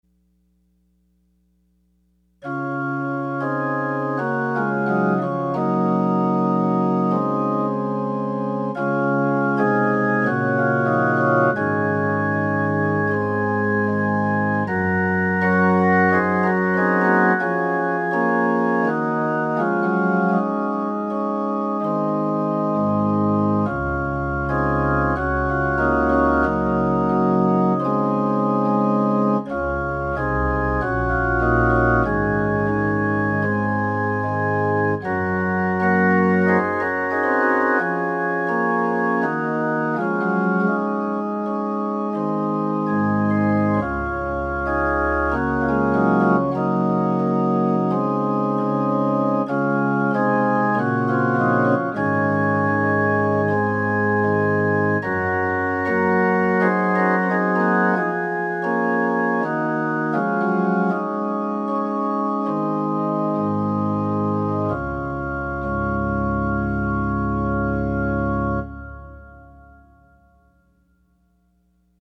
Offering Hymn – Take, O take me as I am